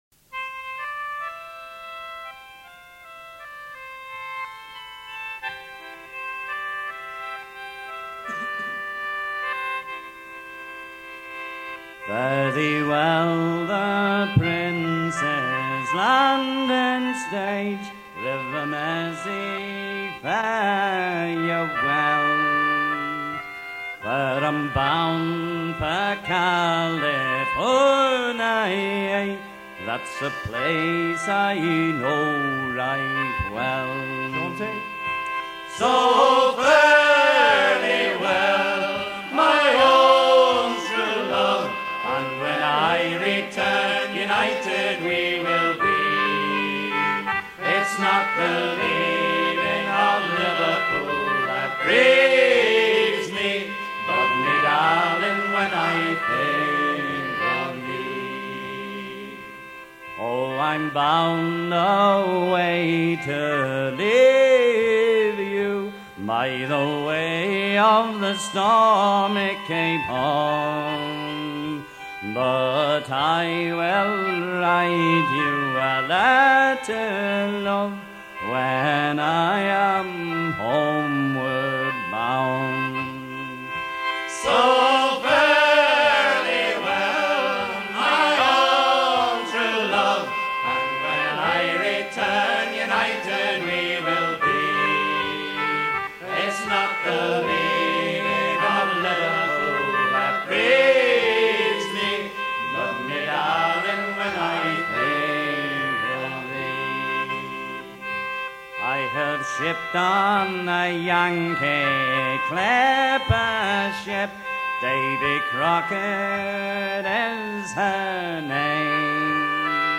chanson
Pièce musicale éditée